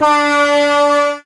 SubwayHornSFX.wav